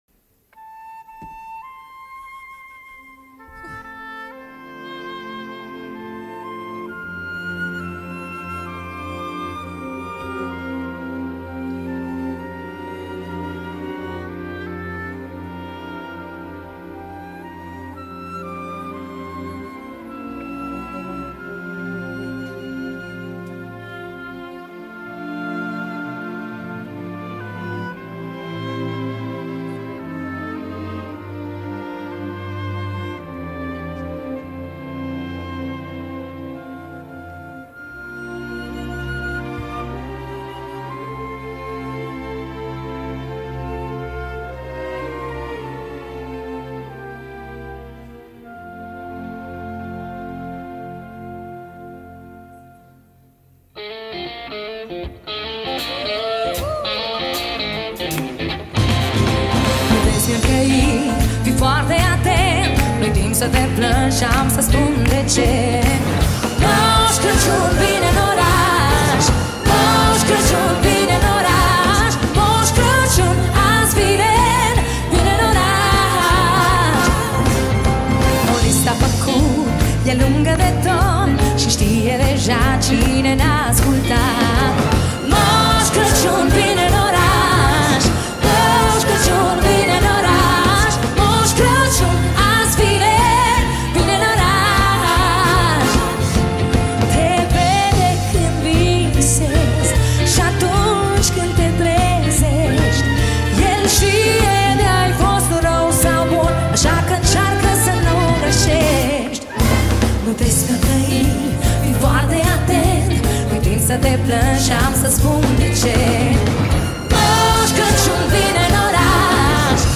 live La Opera Nationala
Data: 12.10.2024  Colinde Craciun Hits: 0